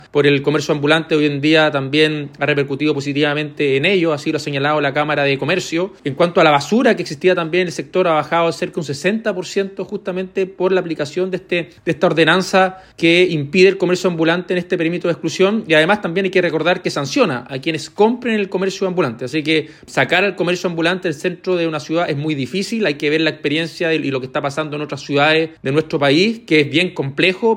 En tanto, el alcalde Rodrigo Wainraihgt admitió la complejidad de acabar con los vendedores irregulares.
rodrigo-wainraihgt-alcalde.mp3